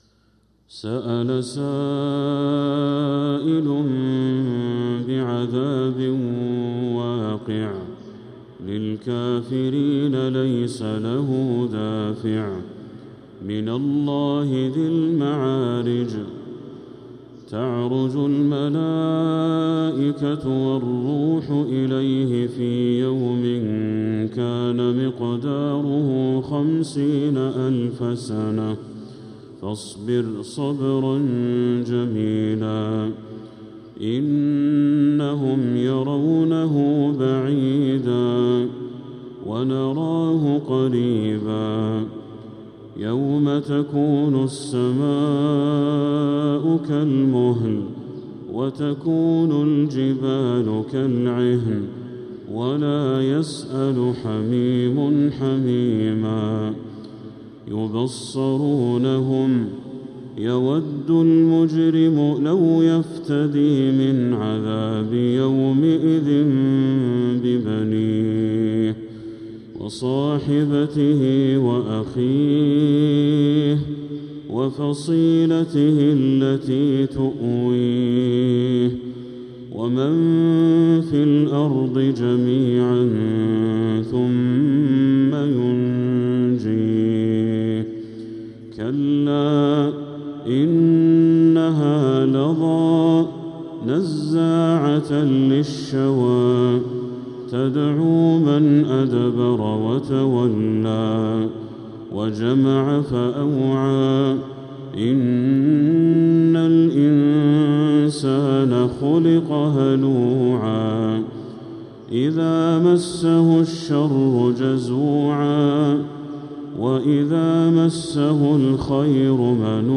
سورة المعارج كاملة | شوال 1446هـ > السور المكتملة للشيخ بدر التركي من الحرم المكي 🕋 > السور المكتملة 🕋 > المزيد - تلاوات الحرمين